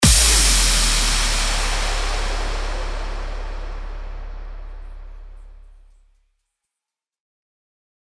FX-937-IMPACT
FX-937-IMPACT.mp3